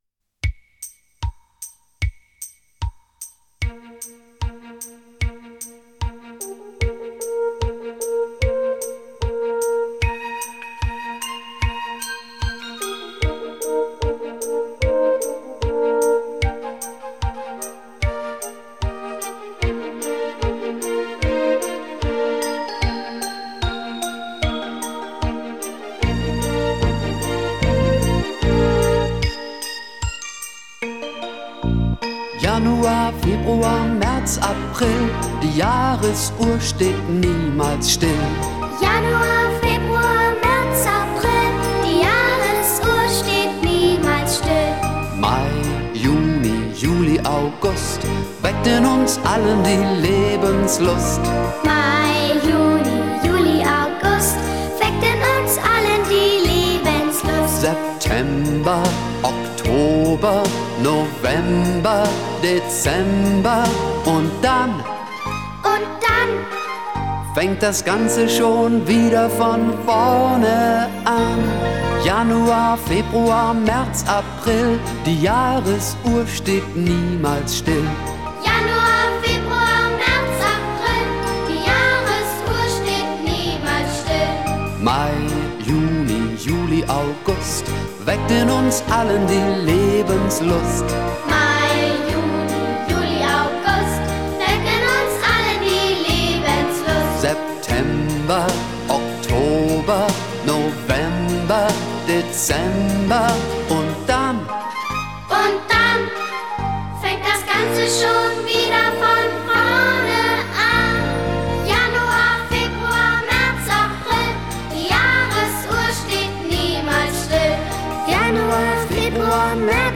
Lied in mp 3